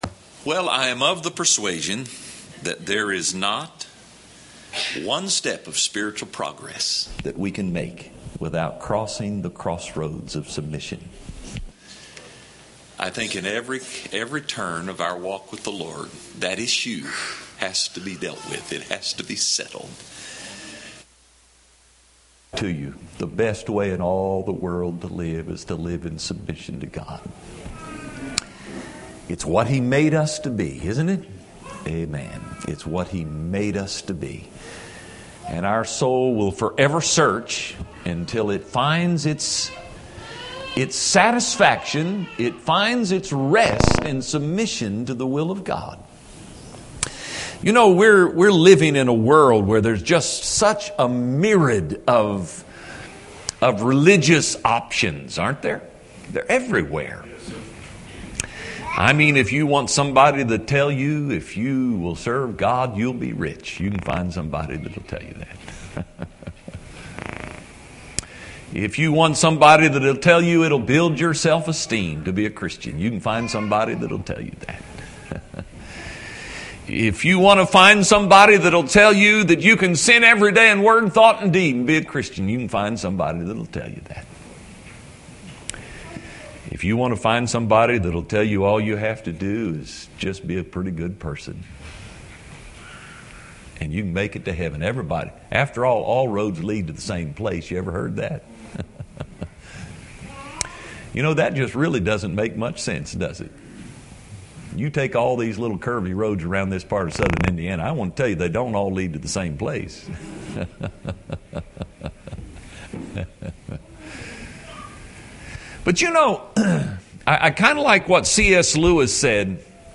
A sermon
Series: Spring Revival 2016 Tagged with cleansing , heart , holiness , sin